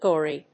/ˈgɔri(米国英語), ˈgɔ:ri:(英国英語)/